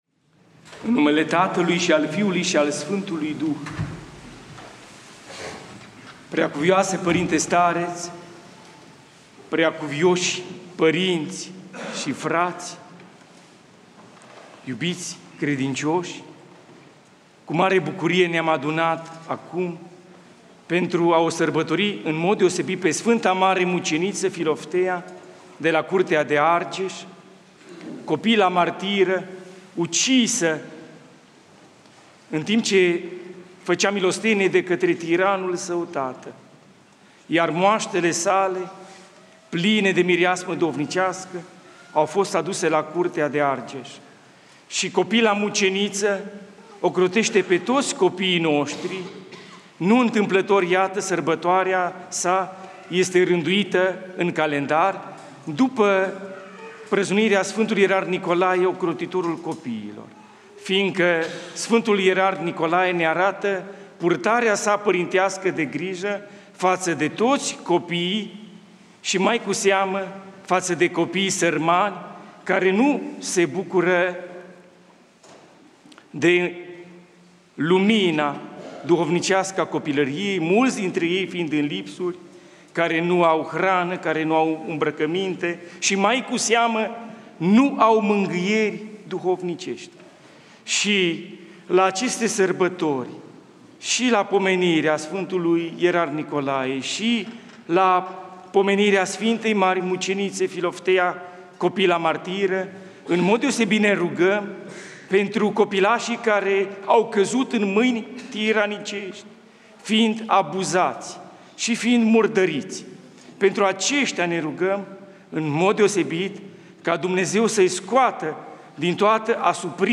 Arhivă de predici la Sf. Mc. Filofteia / ortodoxradio